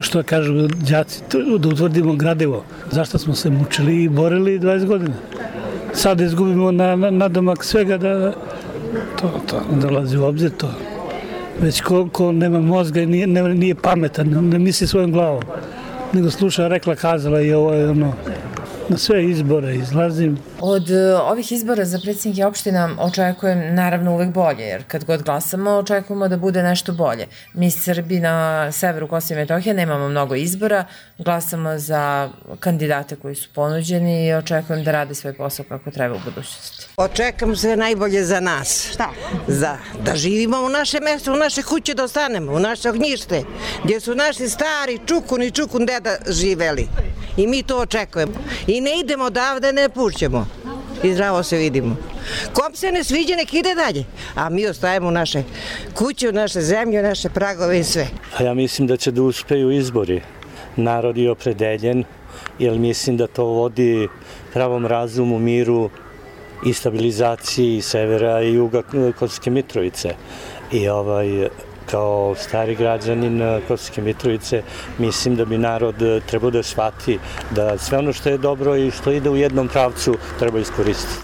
Anketa sa građanima na biralištima u Mitrovici i Zvečanu